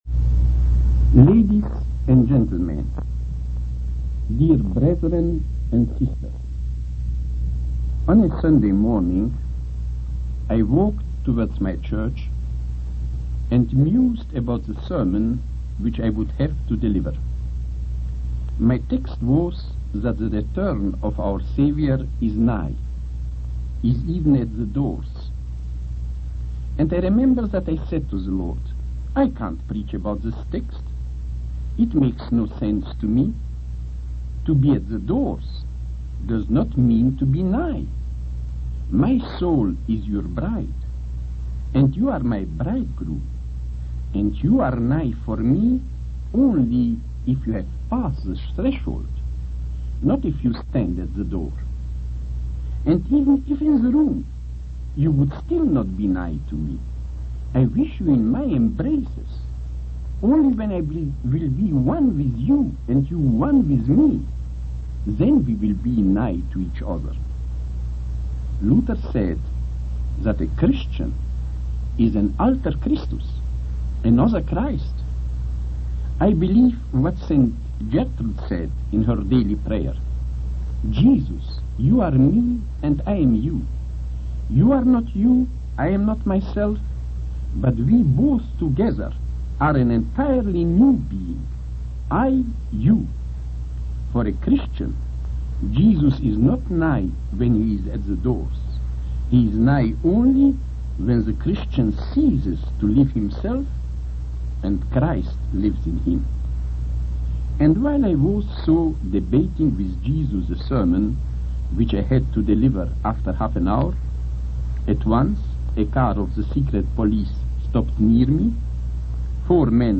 In this sermon, the speaker reflects on their experience of being imprisoned and the thoughts that went through their mind during that time. They come to understand that God allowed them to be in prison to purify them for a higher purpose.